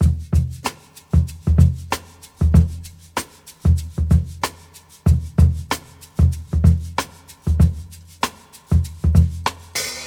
95 Bpm Breakbeat Sample E Key.wav
Free breakbeat sample - kick tuned to the E note. Loudest frequency: 651Hz
95-bpm-breakbeat-sample-e-key-XHe.ogg